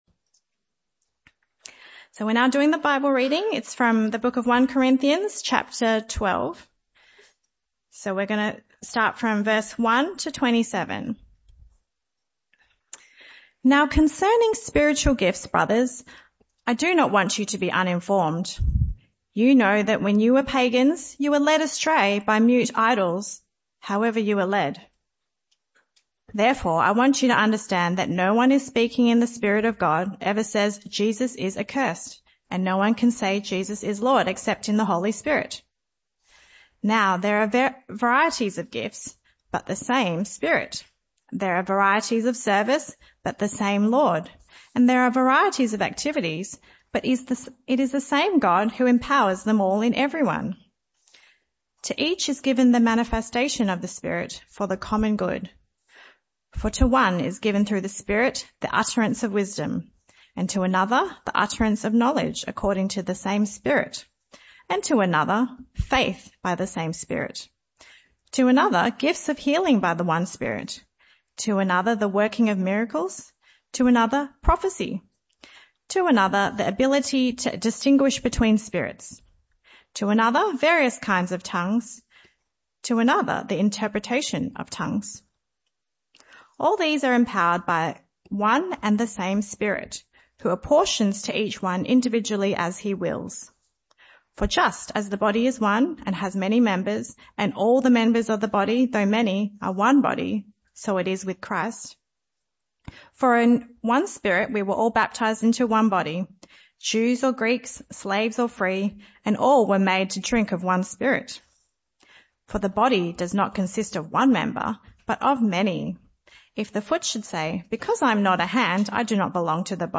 This talk was part of the AM & PM Sermon series entitled 5 Signs Of A Healthy Christian.
Service Type: Morning Service